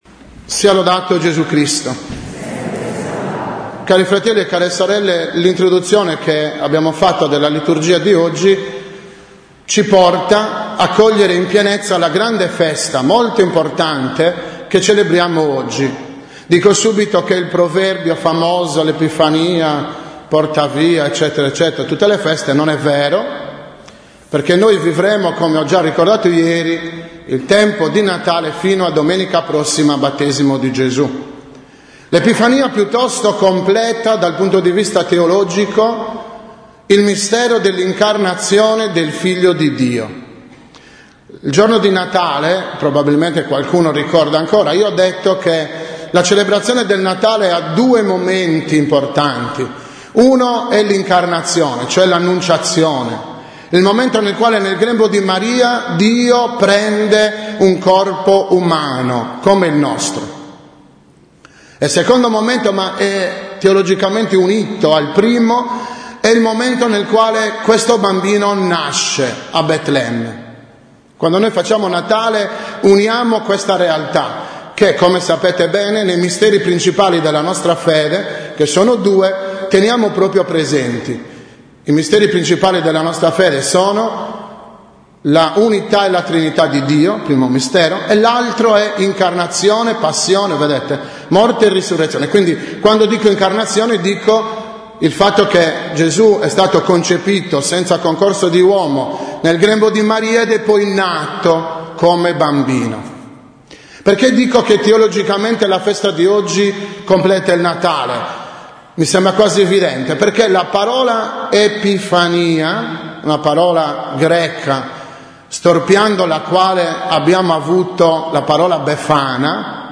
6.01.2014 – OMELIA DELLA SOLENNITÀ DELL’EPIFANIA DEL SIGNORE